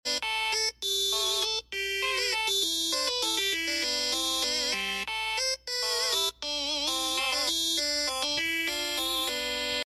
normal sound loop